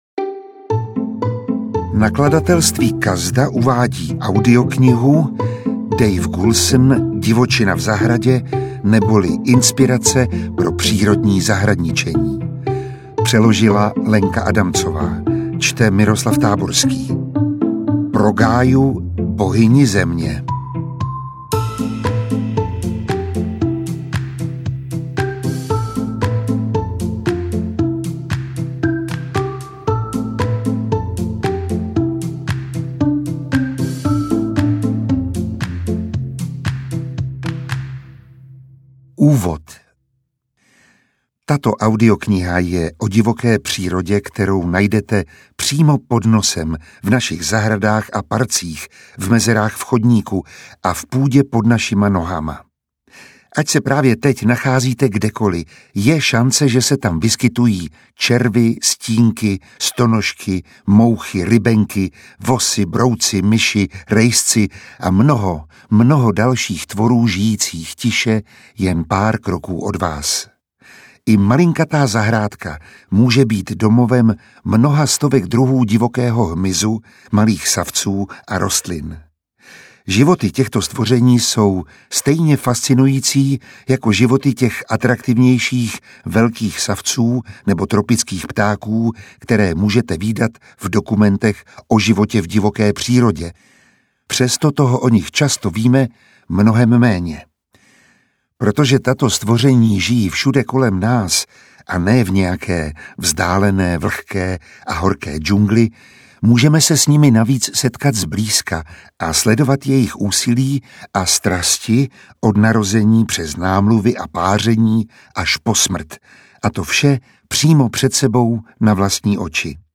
Audioknihu namluvil Miroslav Táborský.